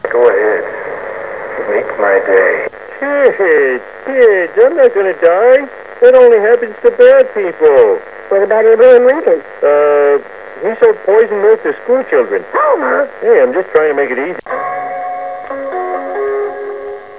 Here is a sample recoded on my Mac microphone from the loudspeaker in the box in the above photograph -
(it contains 2 speech samples and a Windows chime)
I record as loud as possible and just avoiding distortion (like TV adverts!) so as to mask buzzes and clicks from the Sweex.